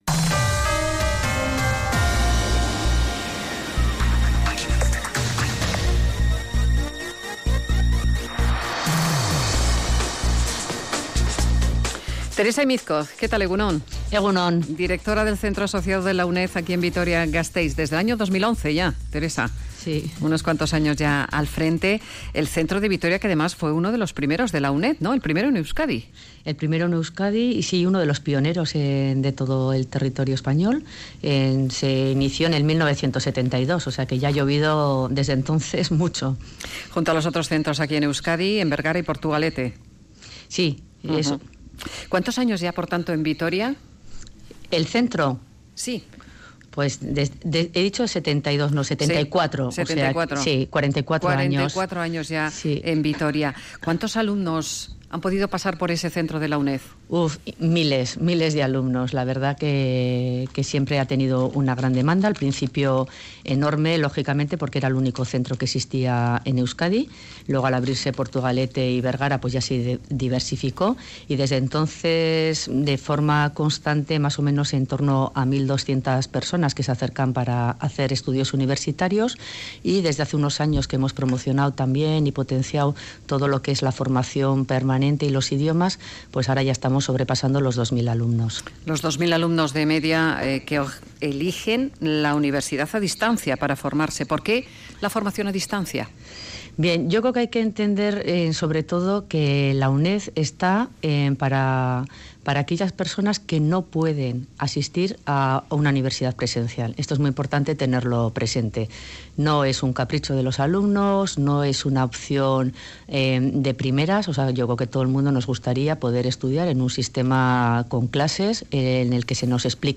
Radio Vitoria ENTREVISTAS Día de la UNED. 44 años en Gasteiz Última actualización: 26/03/2019 12:12 (UTC+1) Una media de 2.000 estudiantes cursan estudios en la UNED de Vitoria.